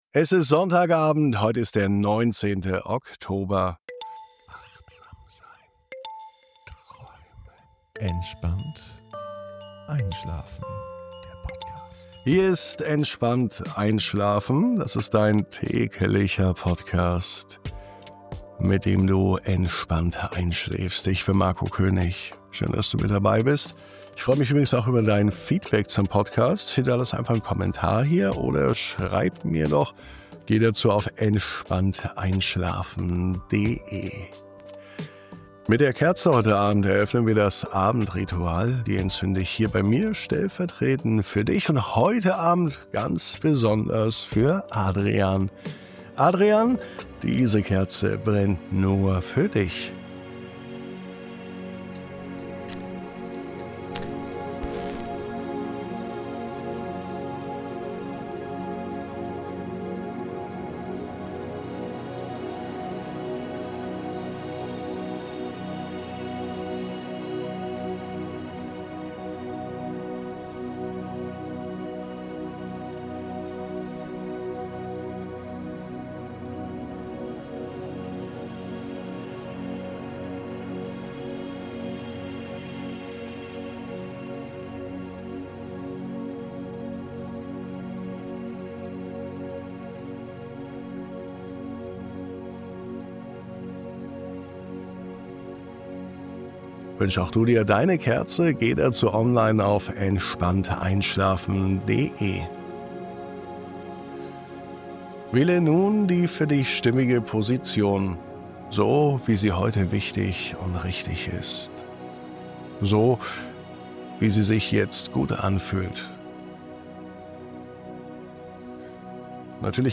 In dieser Folge begibst du dich auf eine stille Reise zu deinem inneren Klang: getragen von sanften Rhythmen und liebevoller Ruhe findest du den Zugang zu deiner Herzensstimme. Ein See, ein stiller Pfad, ein Moment des Ankommens – und dein Schlaf beginnt mit einem tiefen Gefühl von Vertrauen.
1019_MUSIK.mp3